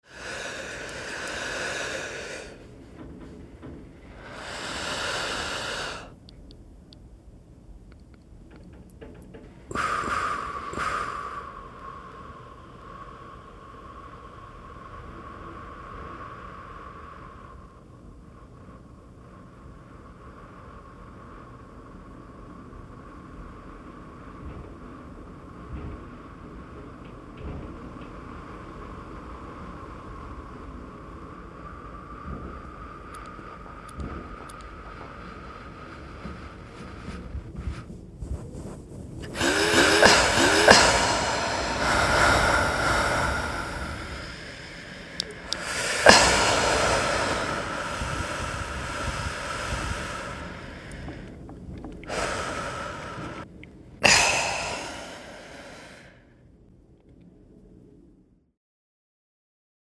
Our homework one week was to create a one-minute piece using field recording. It was supposed to convey an emotionally impactful event from our week…without any talking. For my piece, I tried to convey the feeling of waiting to hear back about something that I really cared about.
I made it out of three one-minute-long voice memos of just the sound of my breathing.